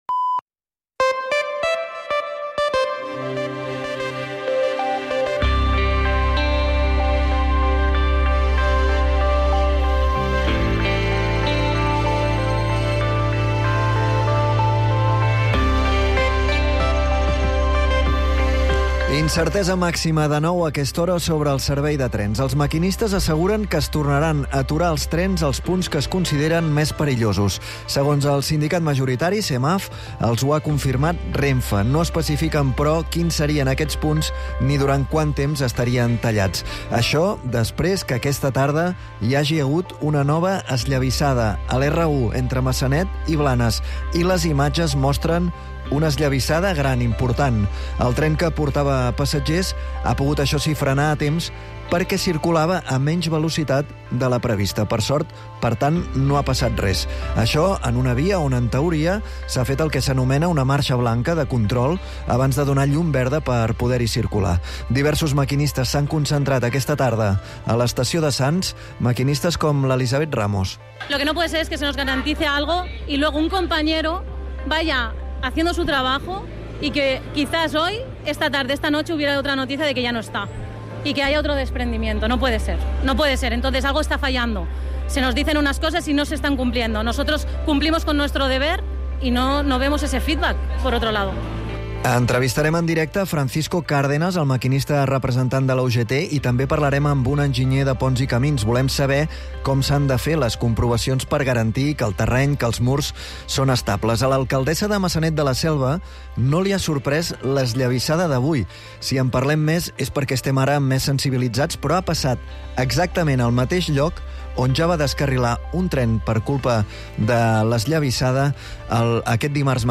El compromís d’explicar tot el que passa i, sobretot, per què passa és la principal divisa del “Catalunya nit”, l’informatiu nocturn de Catalunya Ràdio, dirigit per Manel Alías i Agnès Marquès.